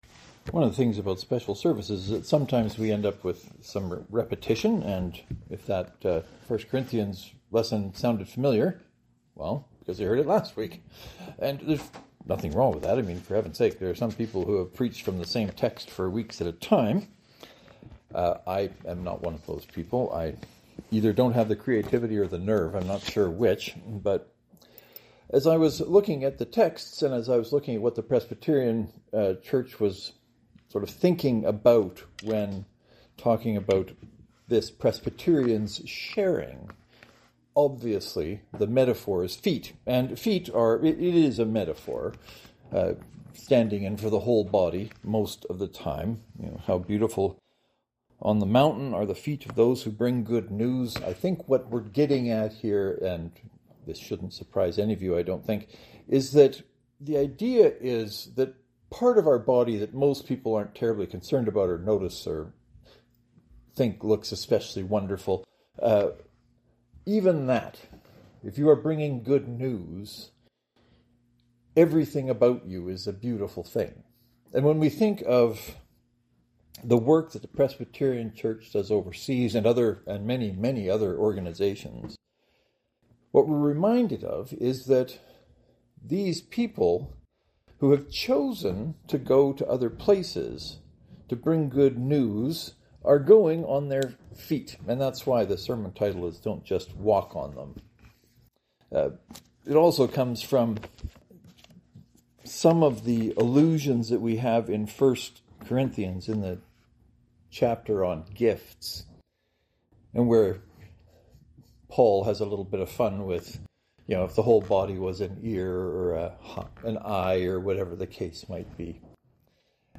The sermon today isn’t entirely about this good news but focuses on how some people work it out.